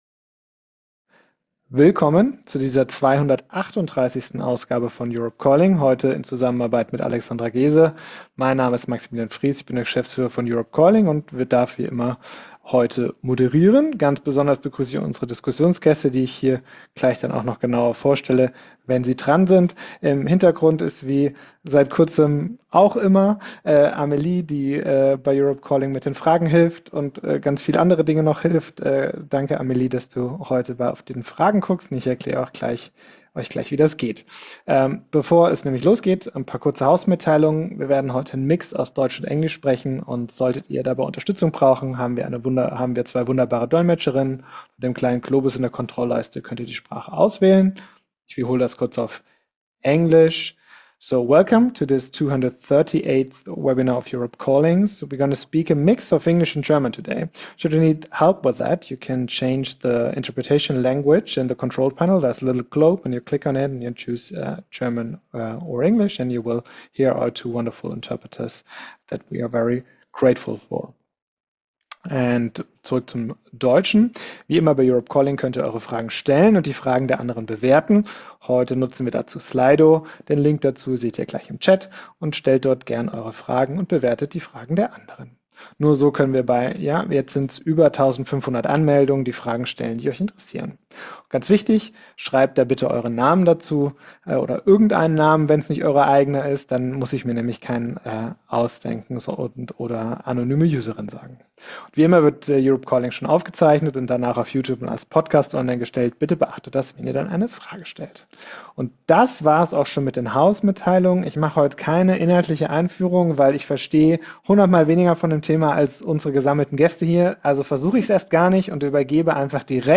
Im gemeinsamen Webinar von Europe Calling e.V. und Alexandra Geese sprechen wir über die aktuelle Dynamik im Rat der EU, die Folgen für unsere Privatsphäre und digitale Sicherheit – und darüber, welche echten Alternativen es gibt.